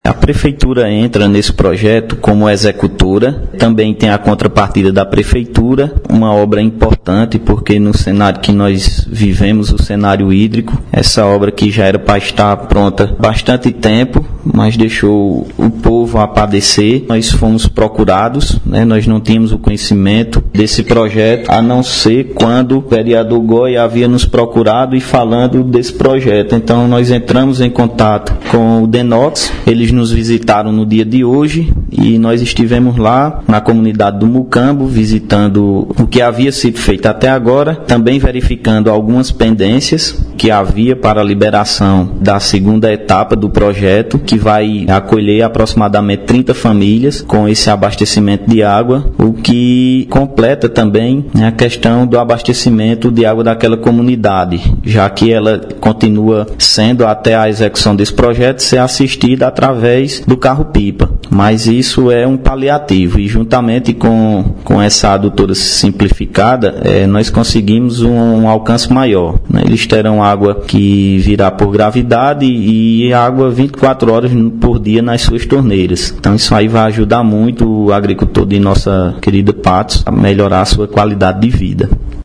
Secretário de Agricultura, João Paulo de Lacerda –